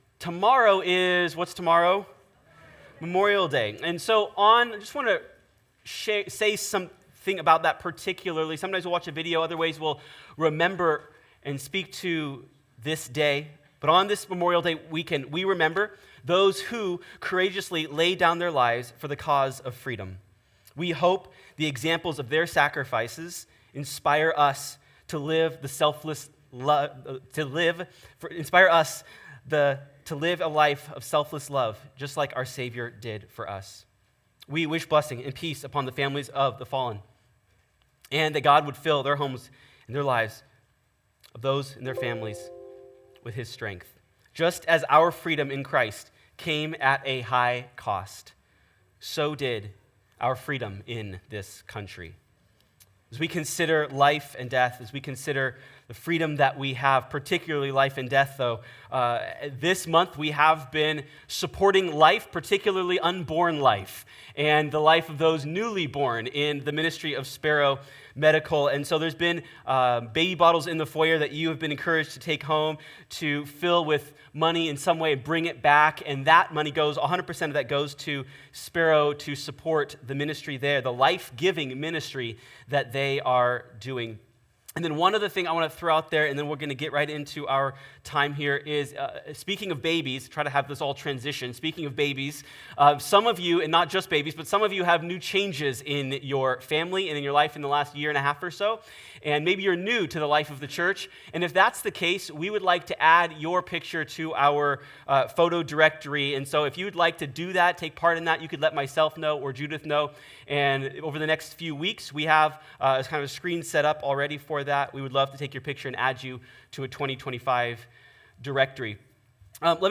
In Jesus' prayer, he shifts his attention to his disciples. As he does, he affirms their saving faith and then asks God to guard and sanctify them as they go into the world. Sermon